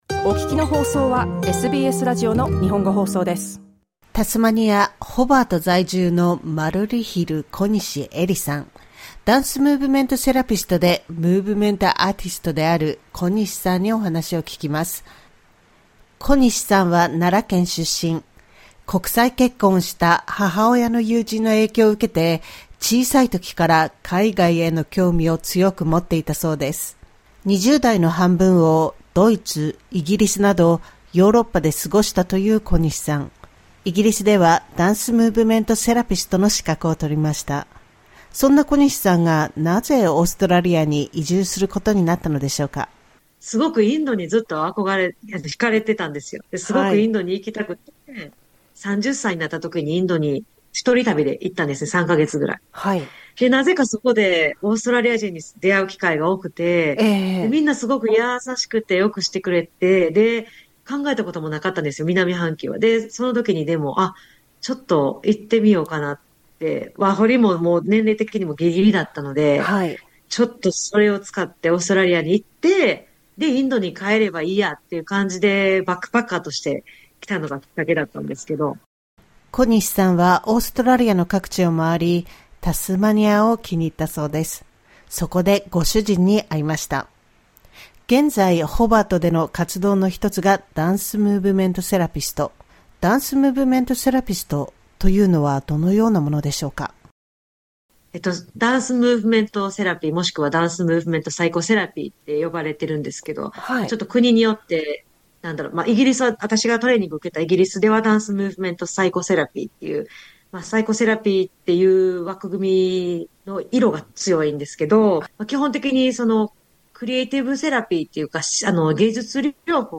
セラピストとして、パフォーマーとしての活動・思いを聞きました。2024年4月放送。